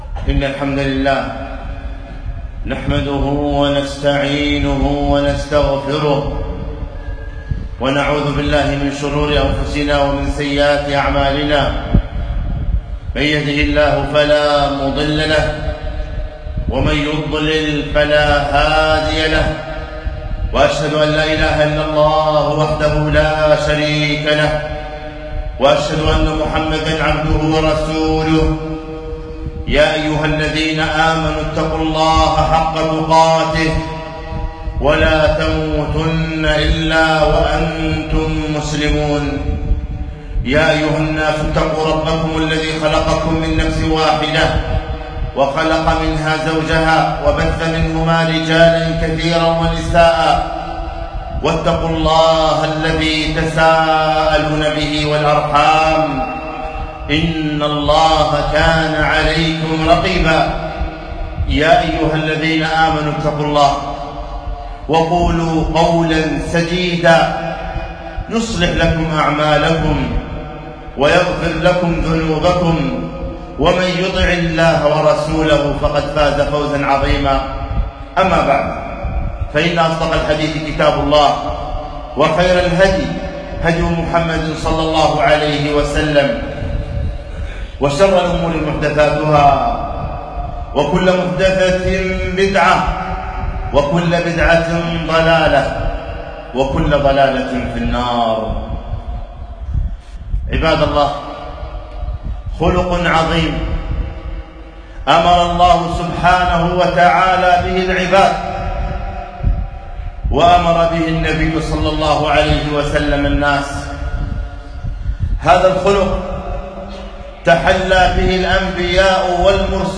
خطبة - إن الله يأمر بالعدل